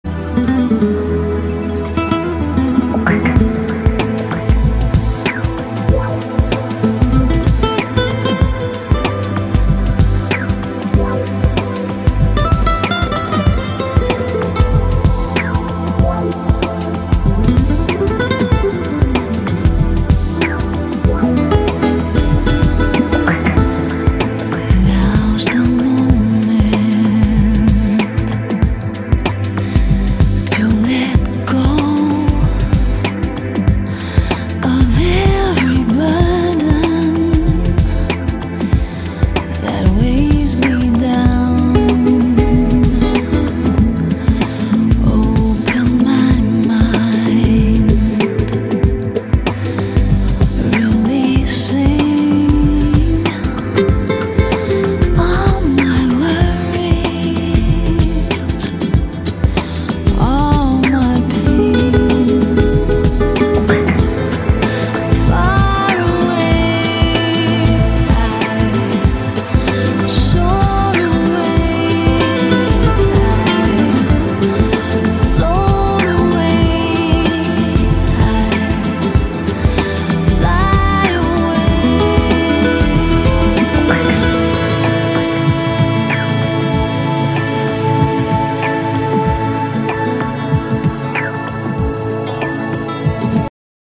романтические